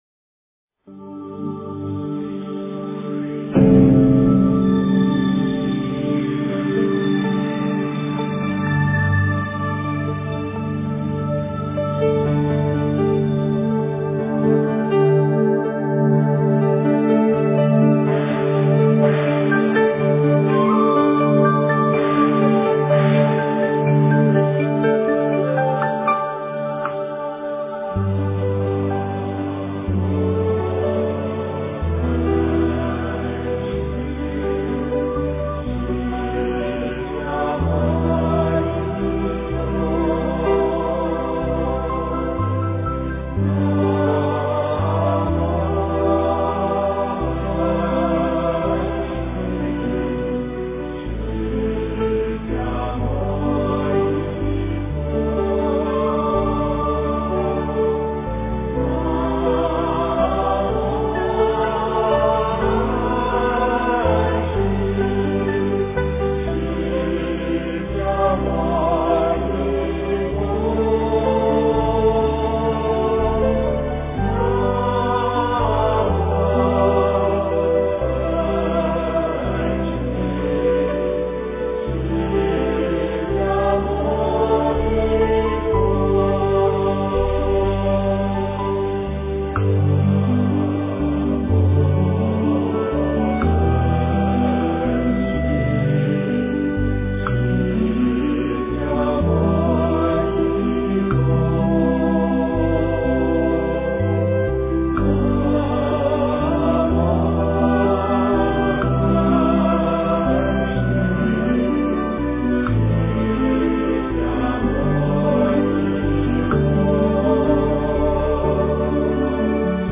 南无本师释迦牟尼佛--慈济功德会 经忏 南无本师释迦牟尼佛--慈济功德会 点我： 标签: 佛音 经忏 佛教音乐 返回列表 上一篇： 普贤行愿品--普寿寺 下一篇： 大悲咒.念诵--普寿寺 相关文章 顶礼南无阿弥陀佛--圆光佛学院众法师 顶礼南无阿弥陀佛--圆光佛学院众法师...